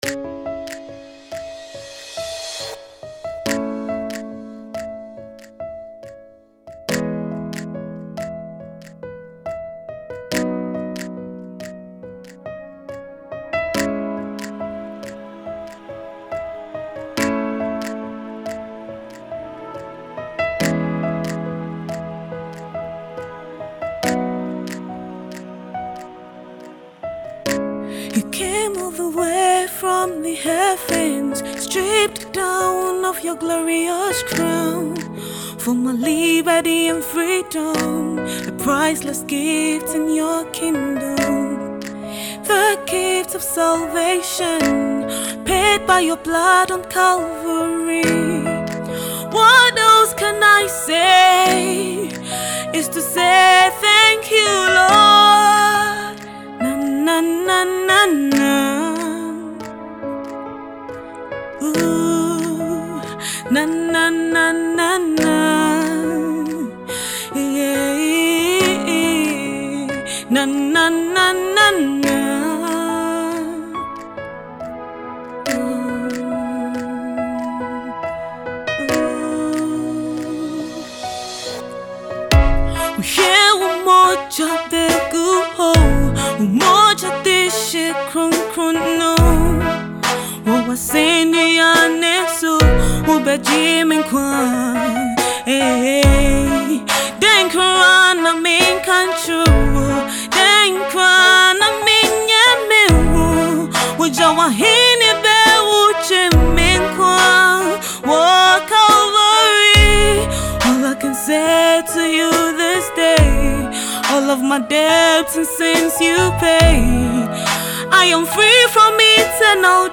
Fast rising Gospel musician